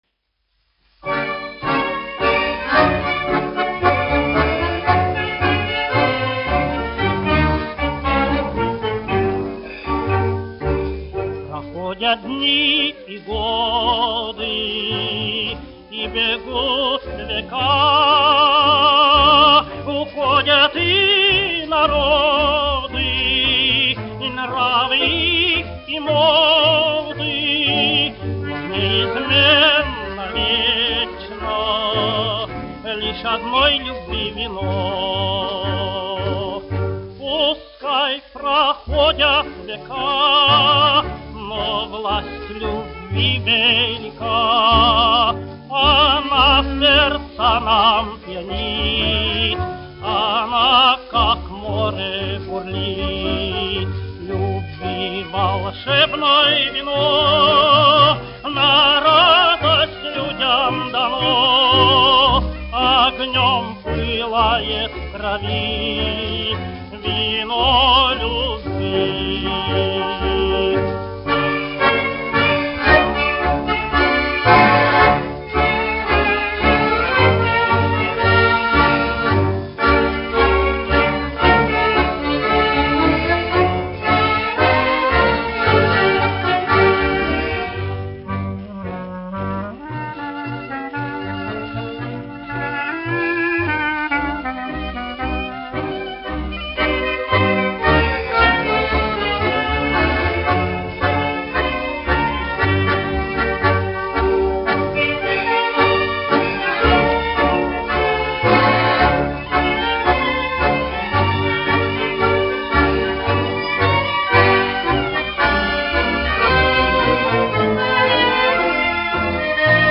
1 skpl. : analogs, 78 apgr/min, mono ; 25 cm
Tango
Populārā mūzika
Latvijas vēsturiskie šellaka skaņuplašu ieraksti (Kolekcija)